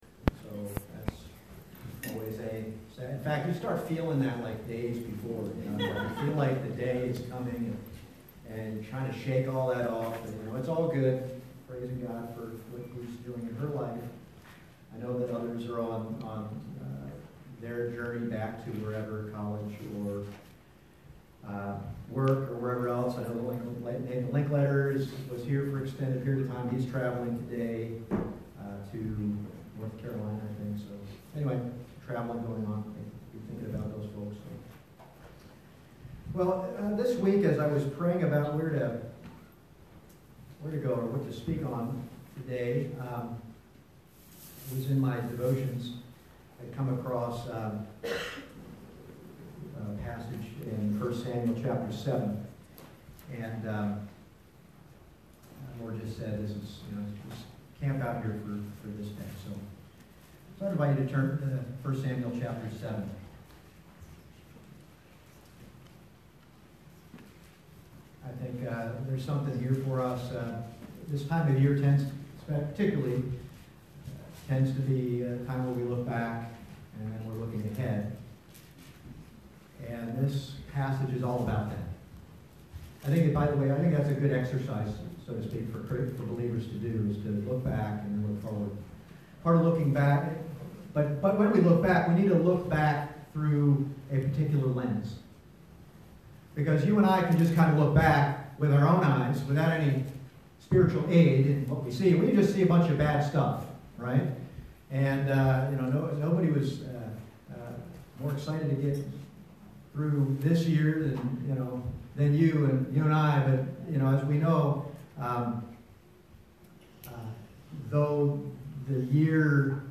Passage: 1 Kings 19:1-18 Service Type: Sunday Morning « The Mighty Whisper of a Still Small Voice Simeon